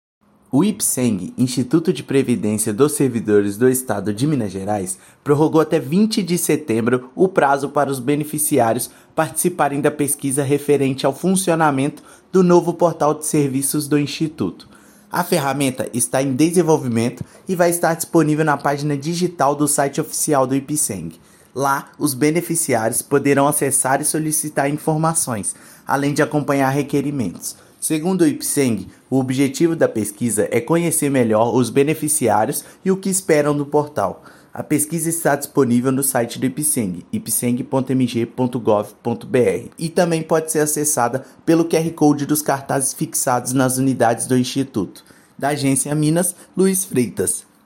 Pesquisa de desenvolvimento da ferramenta foi prorrogada pelo instituto até 20/9. Ouça matéria de rádio.